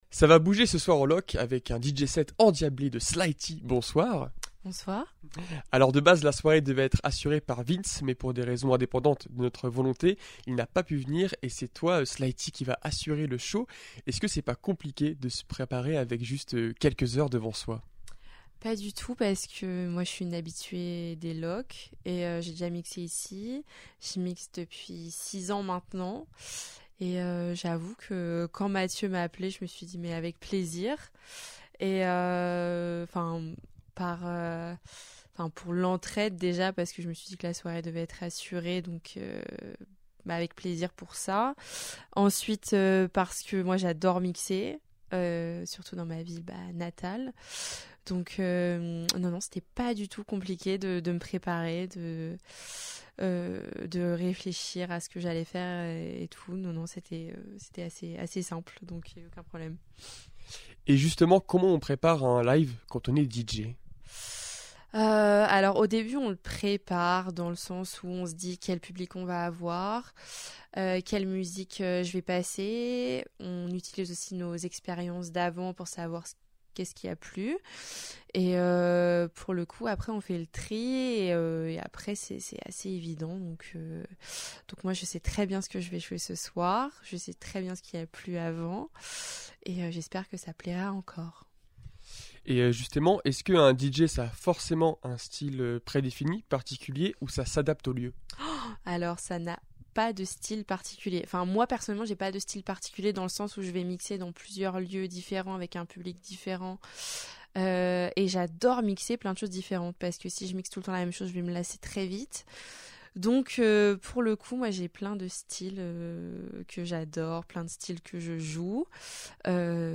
Les interviews Radar Actu Interview fécamp podcast